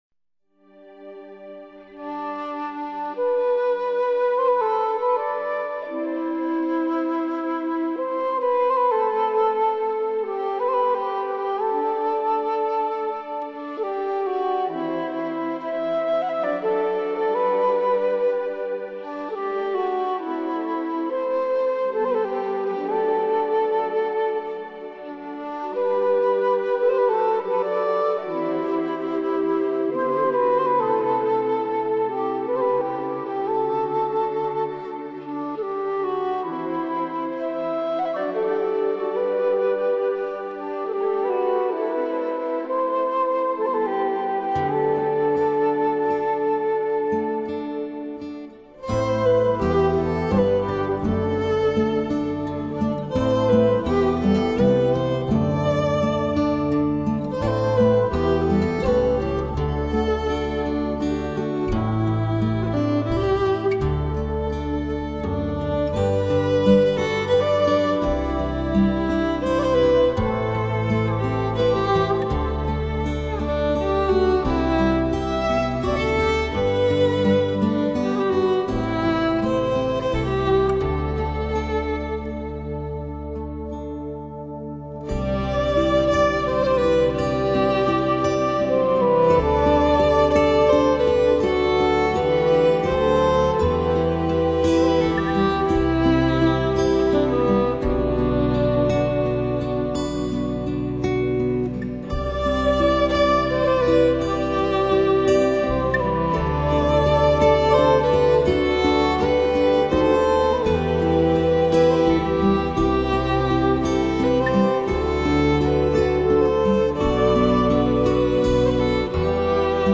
主奏乐器：长笛
★民谣乐风等脍炙人口的曲目